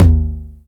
• Low Mid Tom One Shot D# Key 07.wav
Royality free tom one shot tuned to the D# note. Loudest frequency: 261Hz
low-mid-tom-one-shot-d-sharp-key-07-Ect.wav